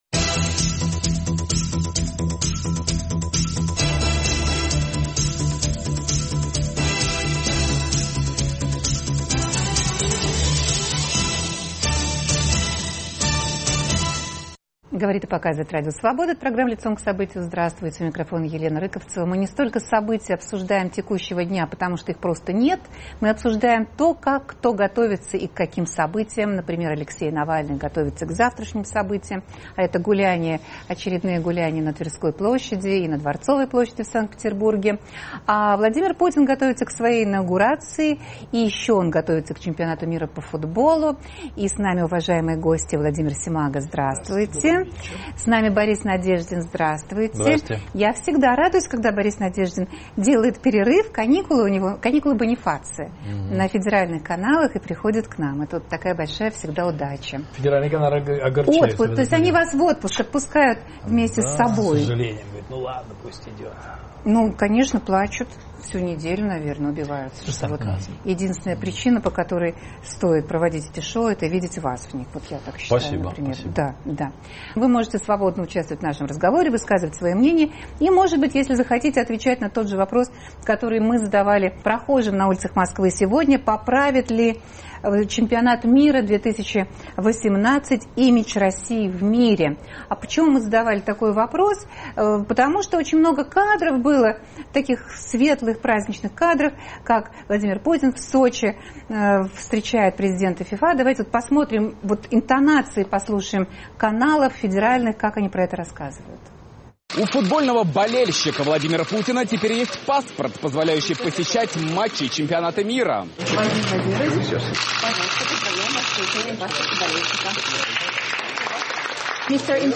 Потеплеет ли западный мир к России на спортивной почве? Обсуждают политики Леонид Гозман, Борис Надеждин, Владимир Семаго.